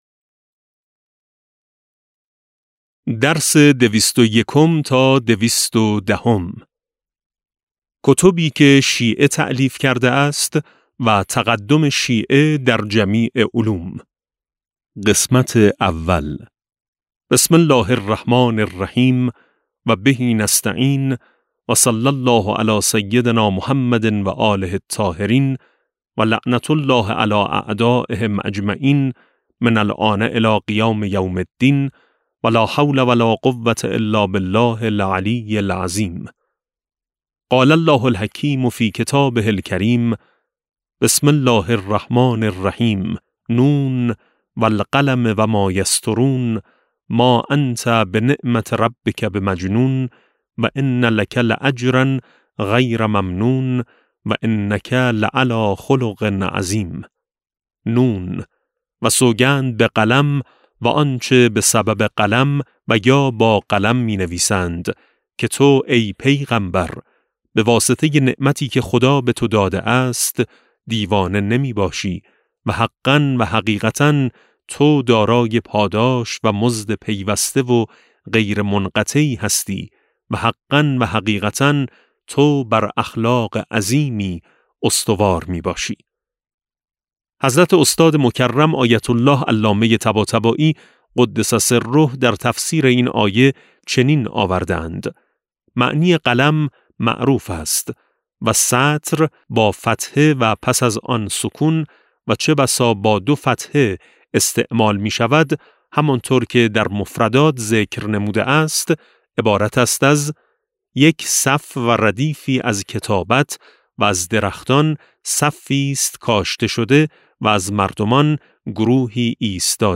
کتاب صوتی امام شناسی ج14 - جلسه9